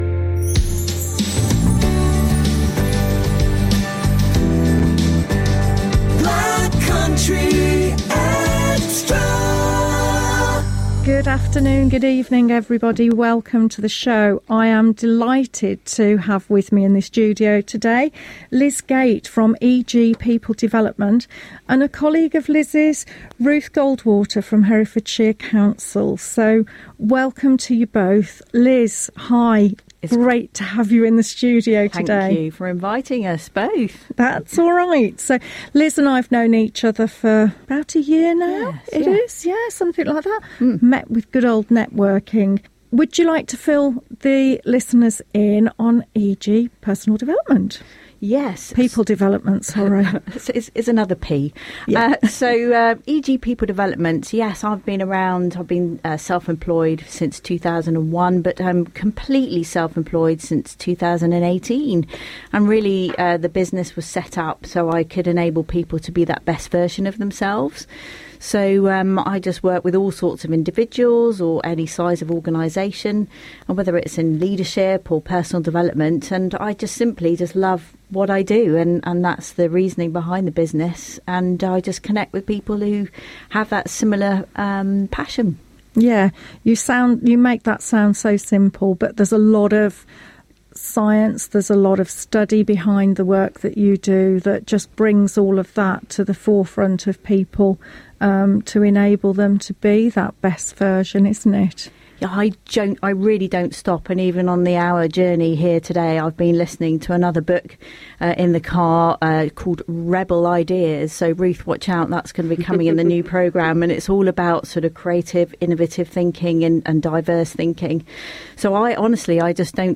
Black Country Xtra Interview